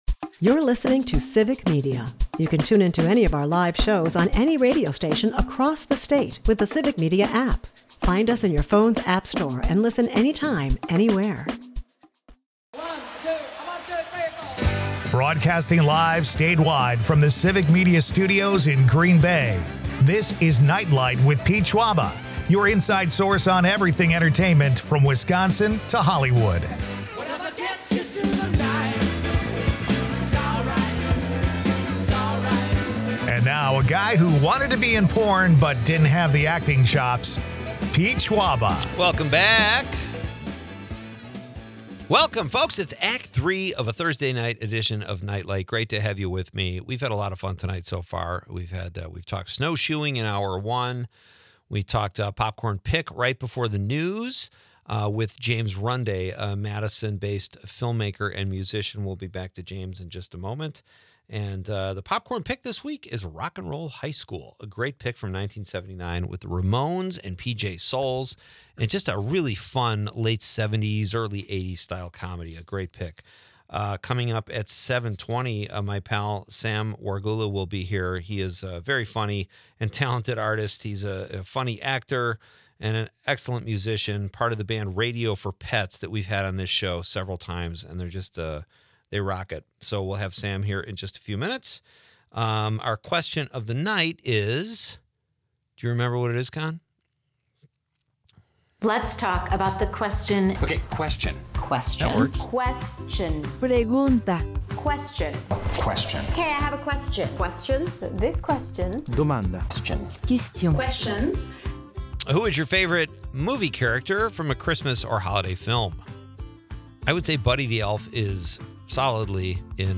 The show features playful games, audience interactions, and musings on classic films like 'The Sopranos'.